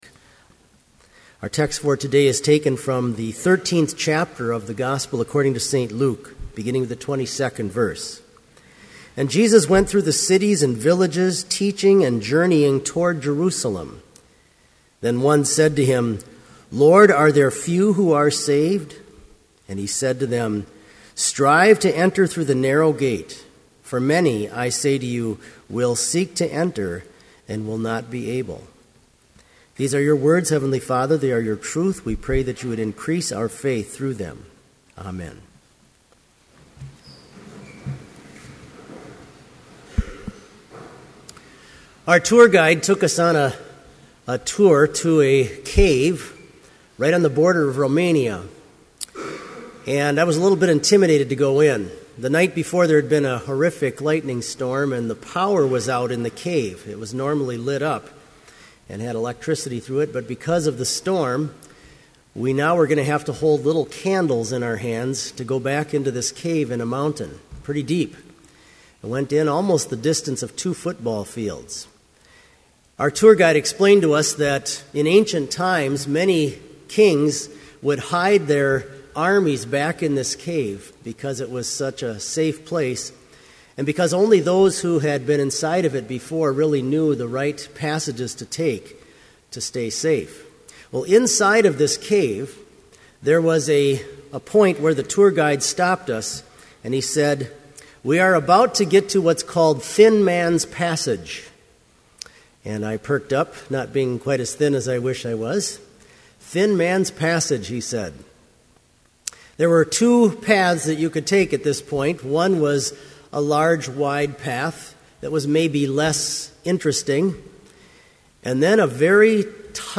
Complete service audio for Chapel - February 22, 2012